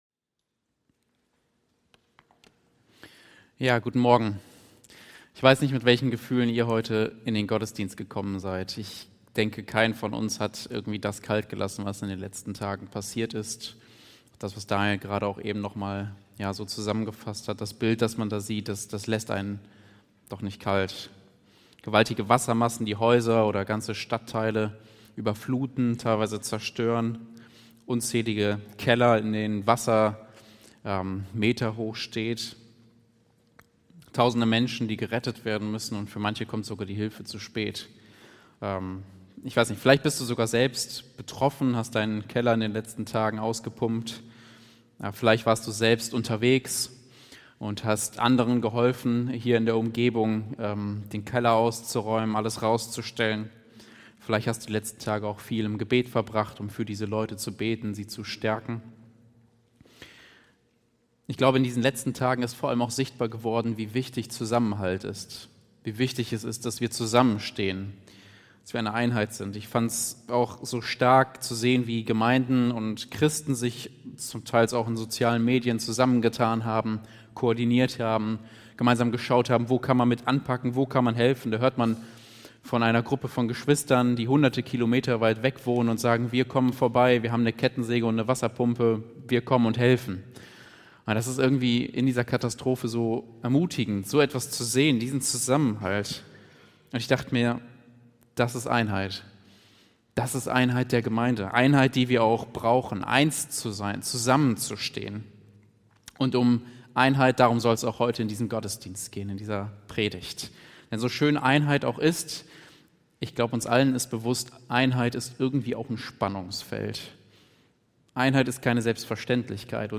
Juli 2021 Predigt-Reihe